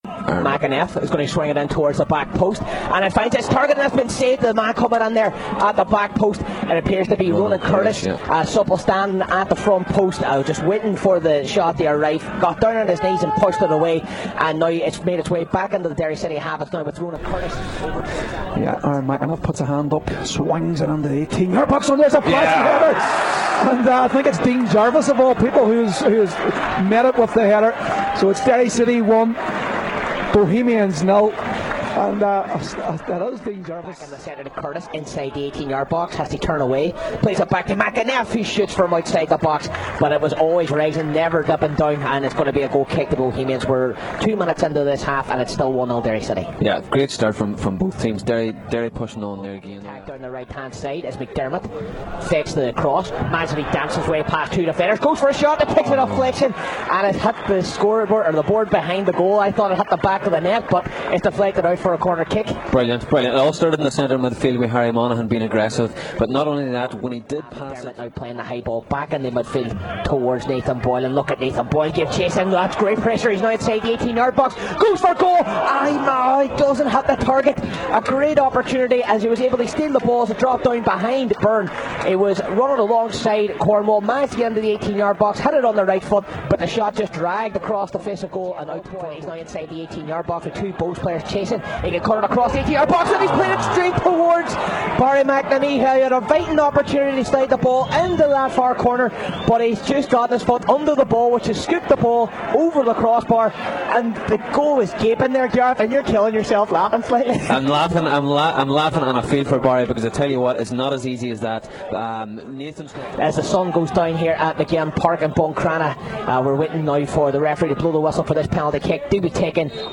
Derry City 2 - 0 Bohemians, Match Highlights plus after match views from Kenny Shiels
Derry got back to winning ways tonight at Maginn Park as they beat the visitors Bohemians by 2 goals to nil. We have match Highlights plus the thoughts of Kenny Shiels shortly after the final whistle.